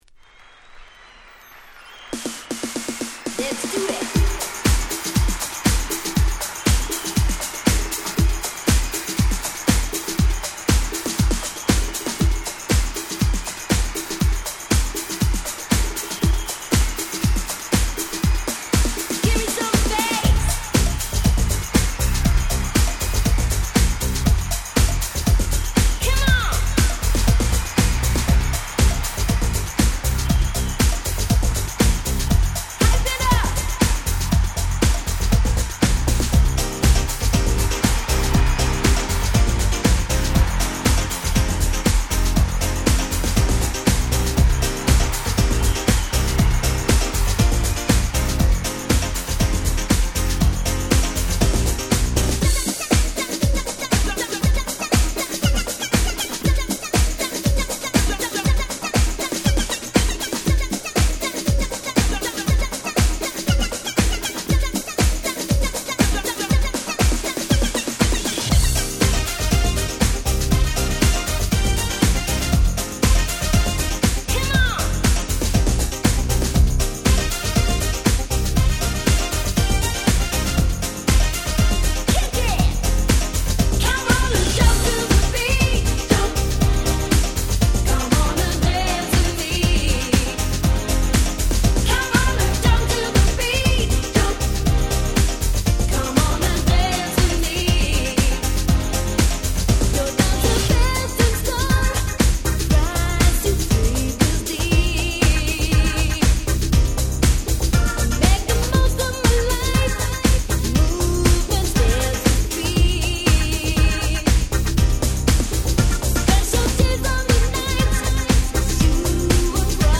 91' Nice UK R&B !!